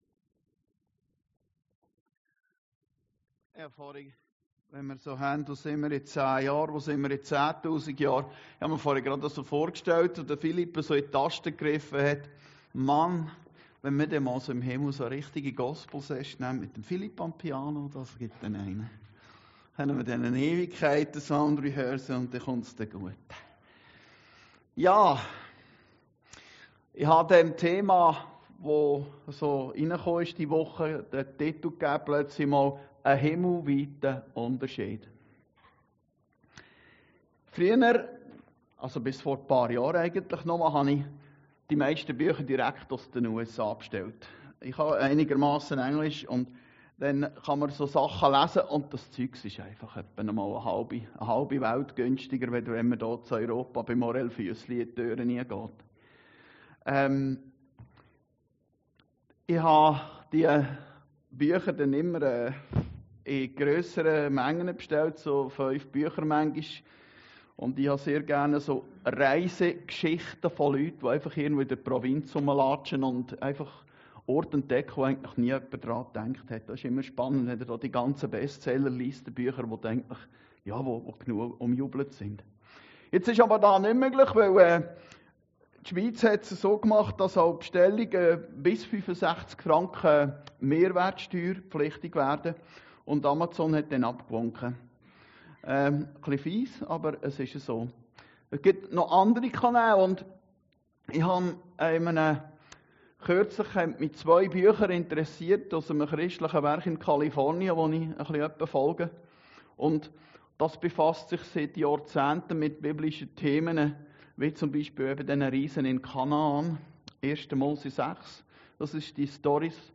Predigten Heilsarmee Aargau Süd – Ein himmelweiter Unterschied